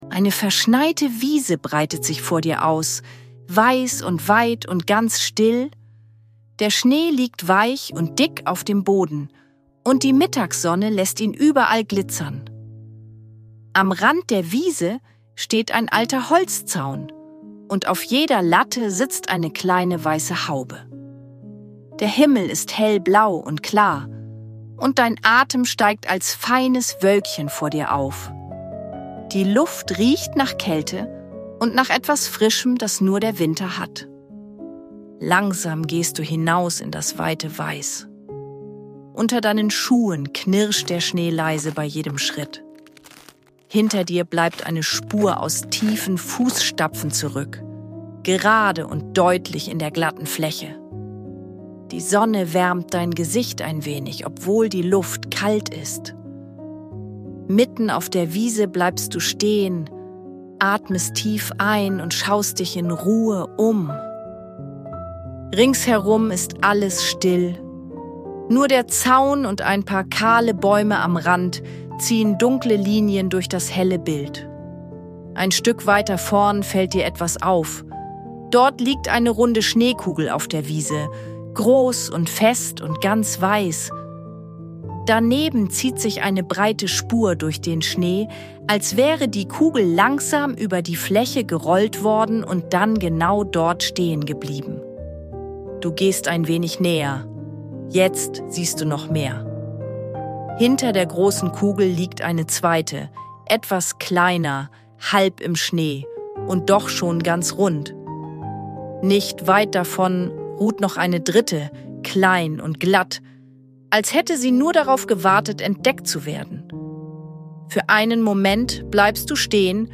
Eine ruhige Fantasiereise zum Malen für Kinder über eine verschneite Winterwiese und einen fröhlichen Schneemann.
Sanfte Fantasiereisen mit leiser Hintergrundmusik – zum Malen und kreativen Entspannen